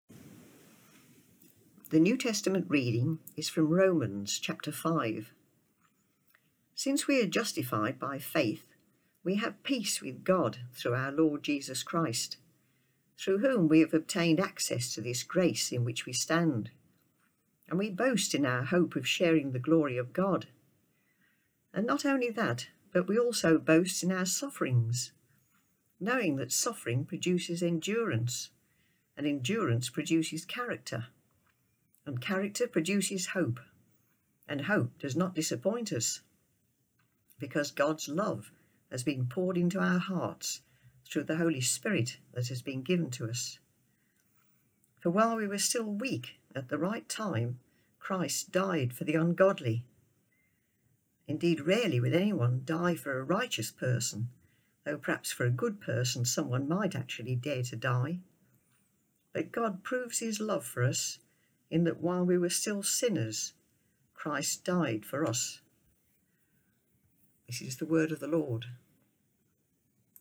New Testament Reading: Romans 5:1-8